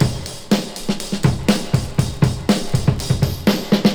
• 121 Bpm Drum Groove B Key.wav
Free drum groove - kick tuned to the B note. Loudest frequency: 1923Hz
121-bpm-drum-groove-b-key-Bvh.wav